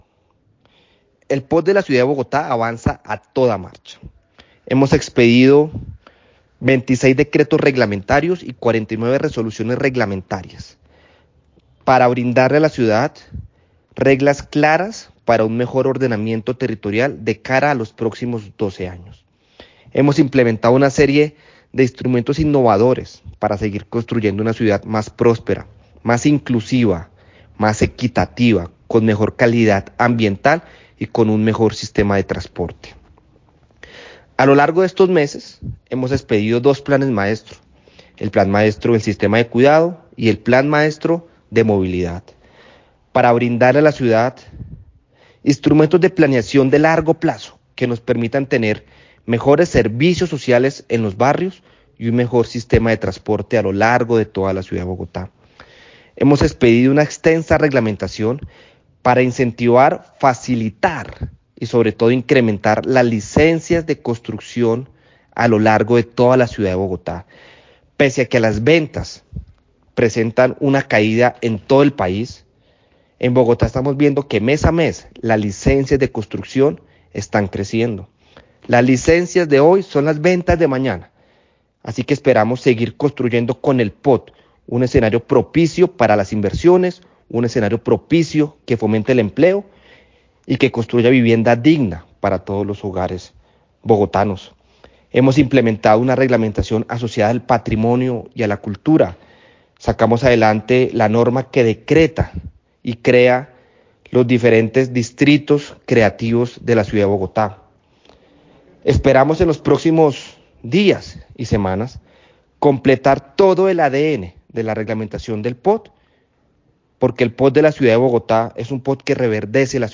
Declaraciones del secretario Distrital de Planeación, Felipe Jiménez Ángel.